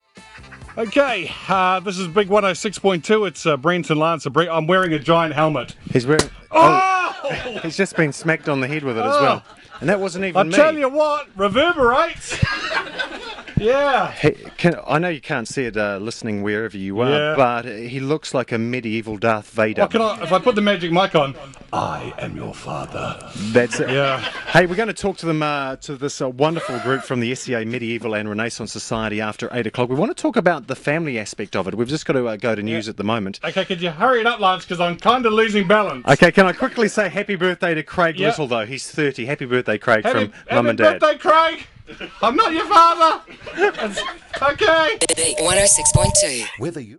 More correctly, the hosts of BigFM invited us to come along and be part of their Friday breakfast show and to talk about who we are and what we do.